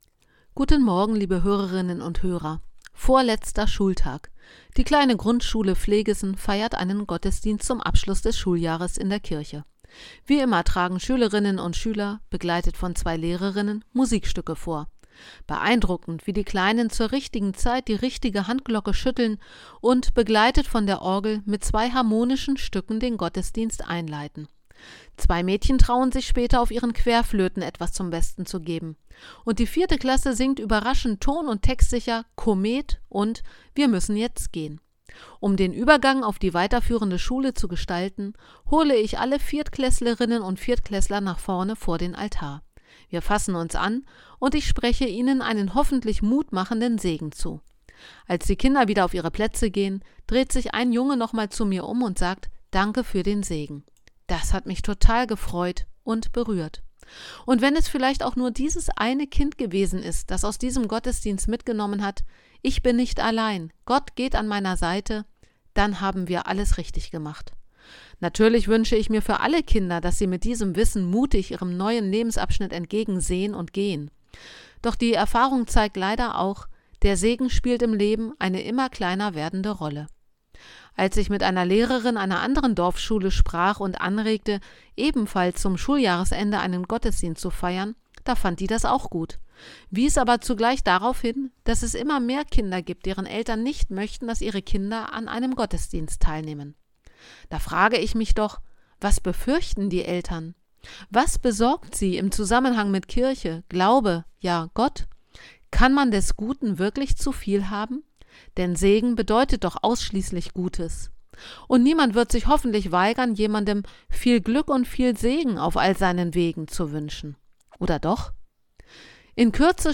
Radioandacht vom 28. Juli